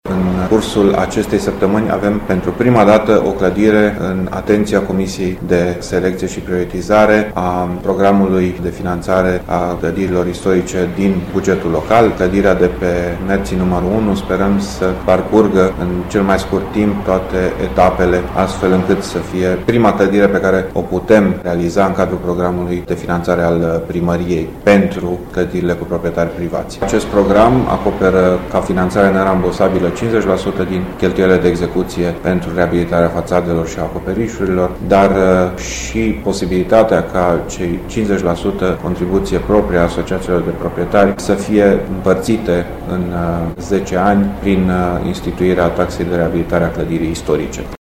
Viceprimarul Dan Diaconu spune ca Primăria Timișoara va plăti 50% din cheltuielile de execuție, restul sumei urmând sa fie achitată de asociația de proprietari.